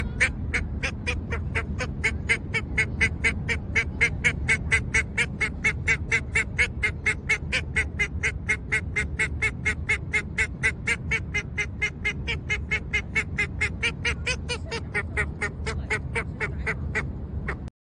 柯尔鸭母鸭叫声